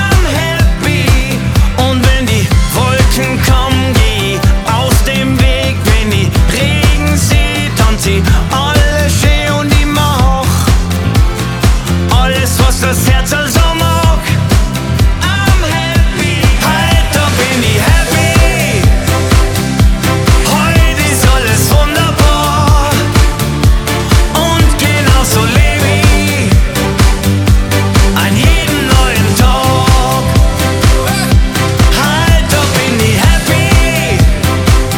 Жанр: Поп музыка
German Pop